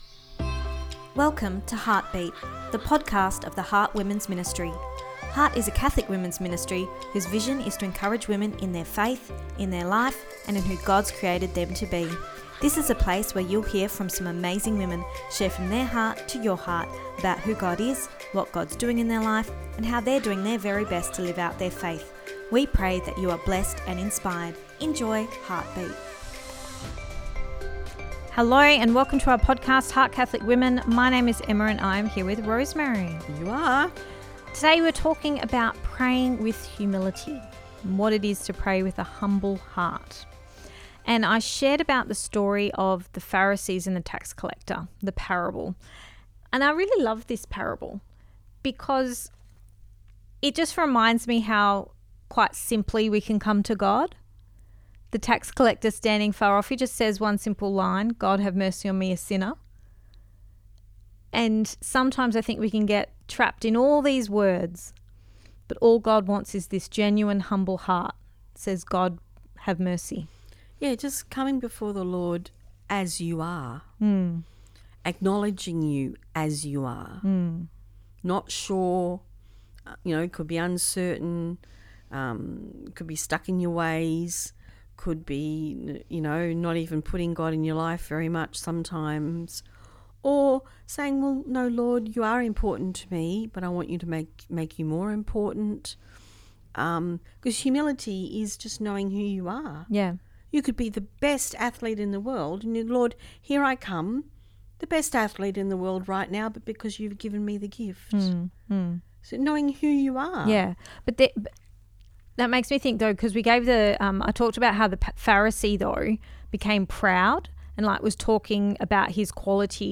Ep281 Pt2 (Our Chat) – Pray with Humility